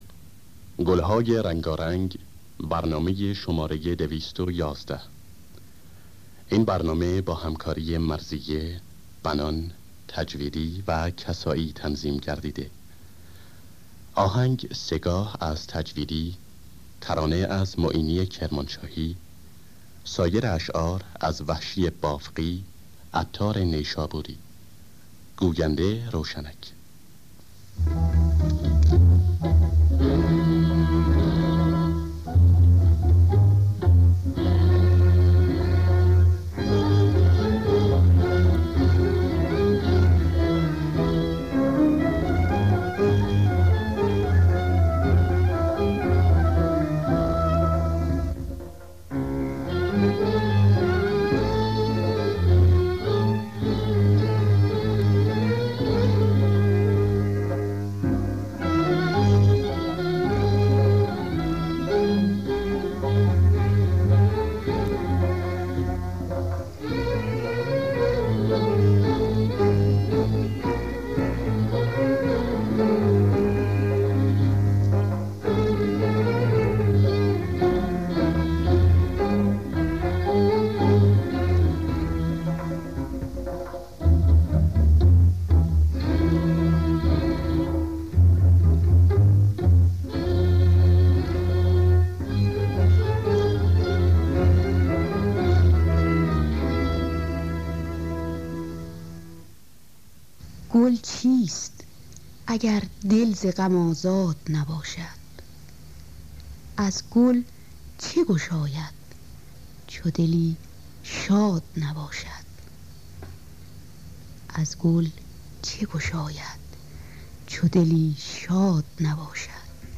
در دستگاه سه‌گاه